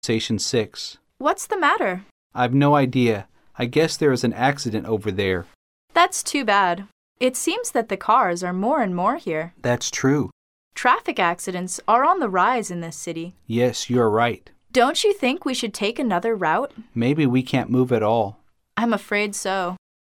Conversation 6